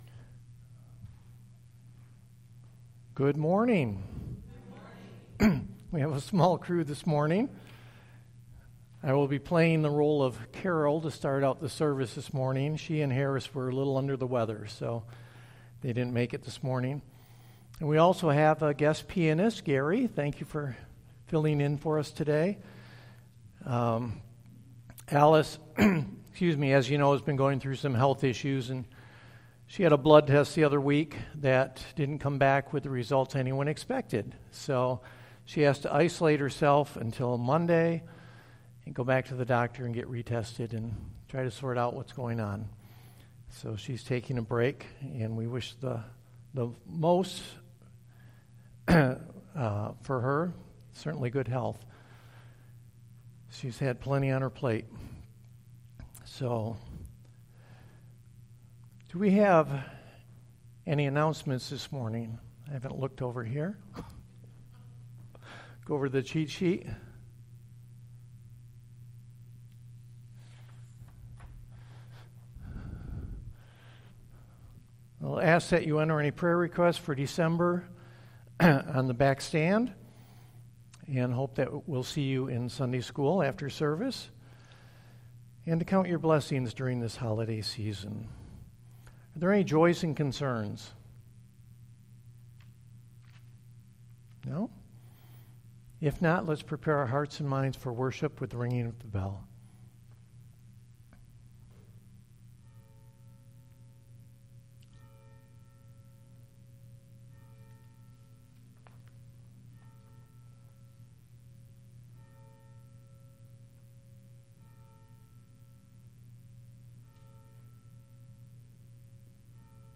Resurrection? 2/13/22 Service